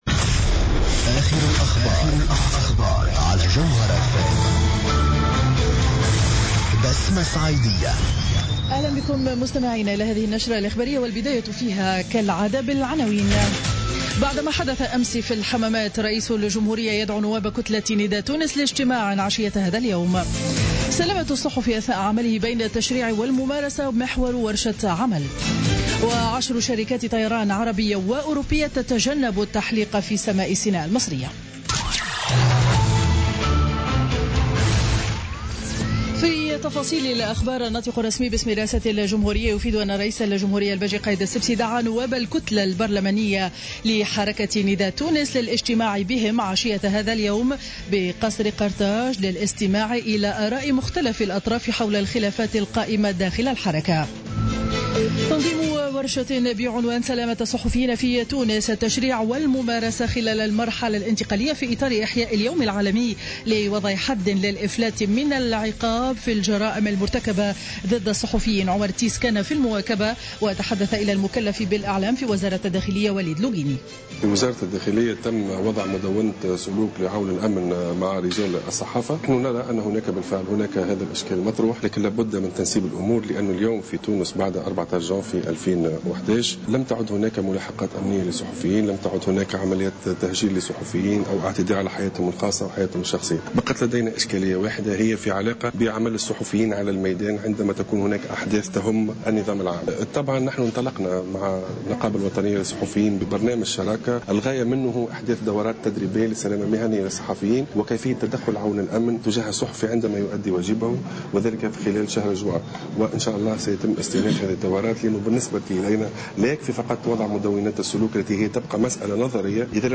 نشرة منتصف النهار ليوم الاثنين 02 نوفمبر 2015